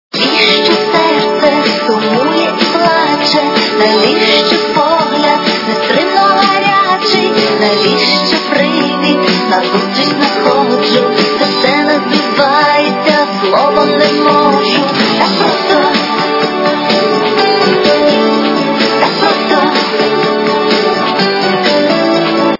- украинская эстрада
качество понижено и присутствуют гудки